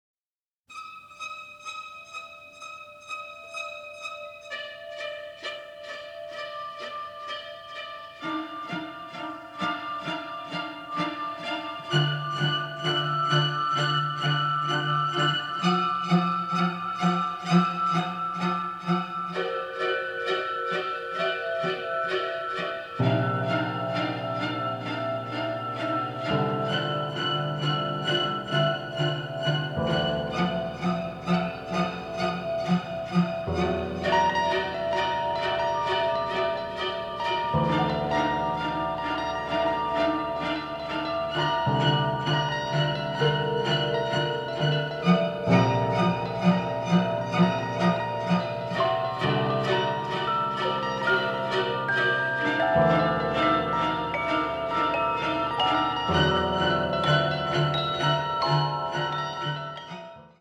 sophisticated avant-garde sound